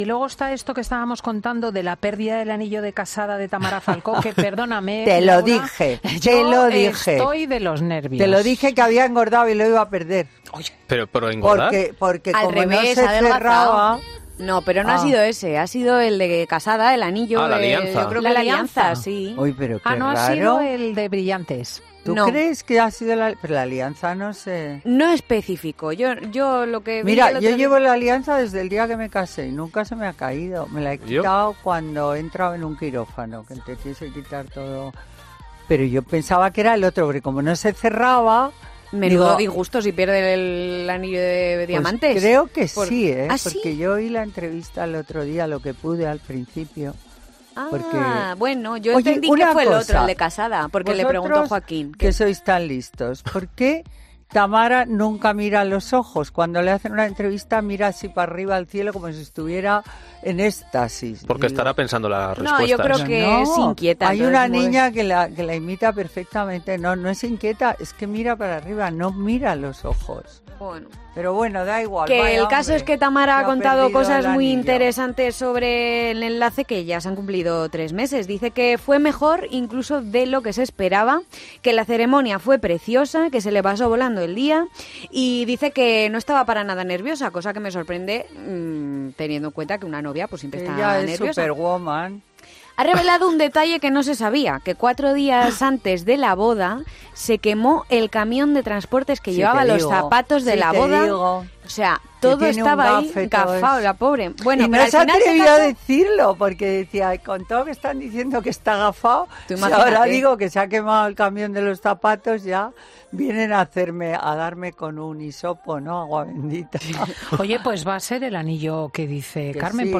Cada sábado, Carmen Lomana analiza la actualidad de la crónica social en 'Fin de Semana' con Cristina López Schlichting.